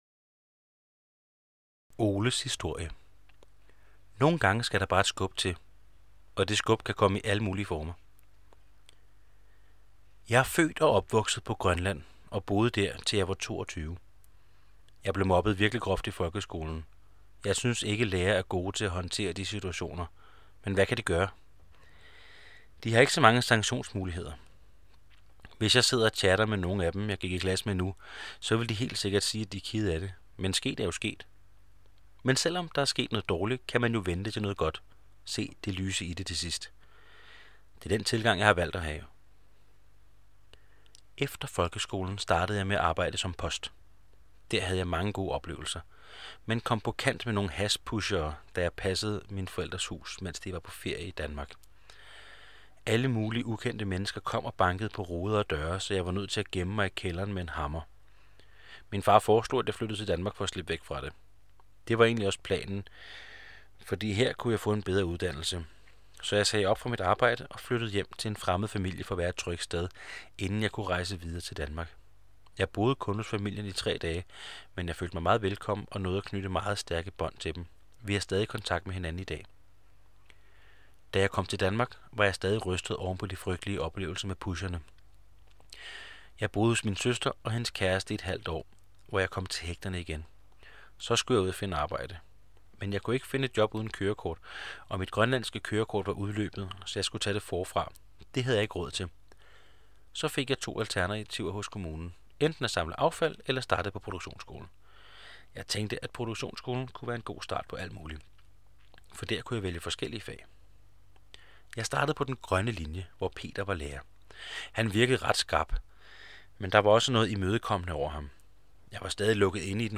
oplæsning